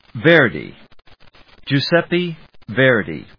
/véɚdi(米国英語), Giu・sep・pe dʒuːsépi(英国英語)/